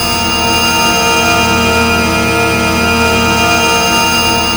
A#4 BOWED07R.wav